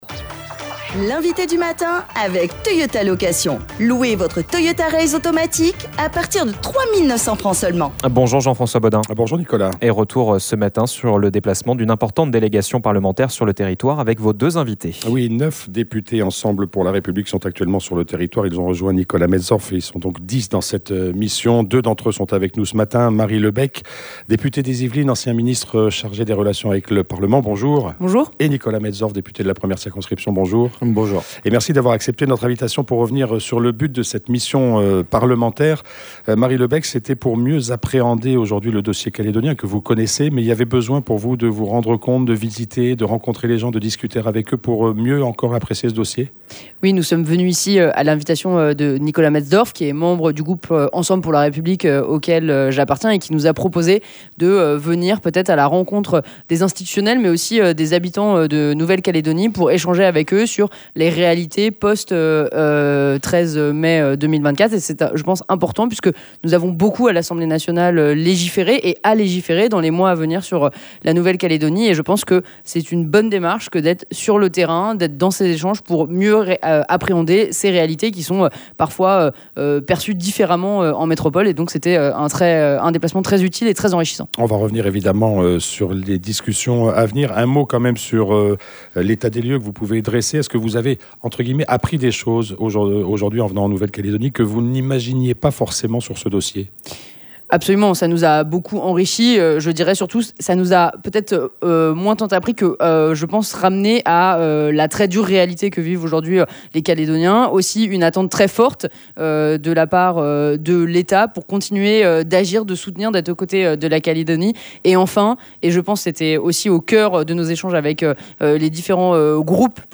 Comment les députés comptent-ils accompagner la mise en œuvre de l’accord de Bougival ? Ce matin, nous en avons parlé avec nos 2 invités. Nous recevions Marie Lebec député Ensemble pour la République de la 4ème circonscription des Yvelines, ancienne ministre des relations avec le parlement dans le gouvernement de Gabriel Attal, et son collègue de Nouvelle-Calédonie, Nicolas Metzdorf, député de la 1ère circonscription.